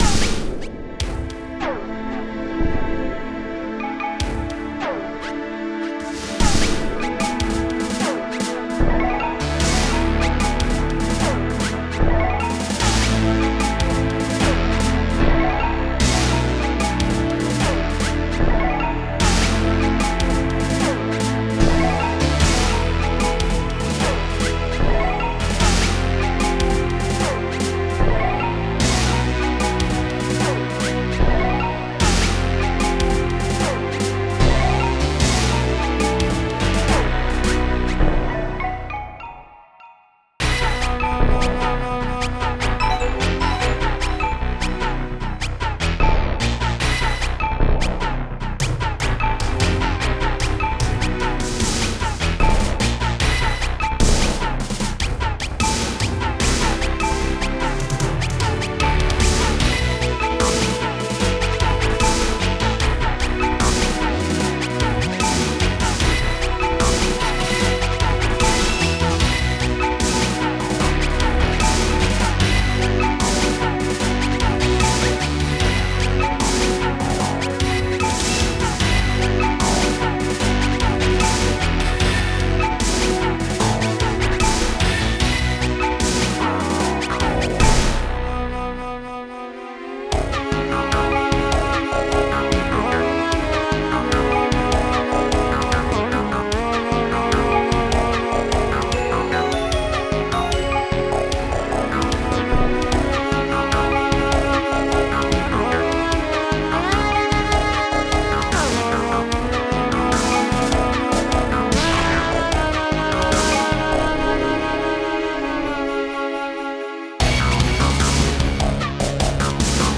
Style: Amiga Remix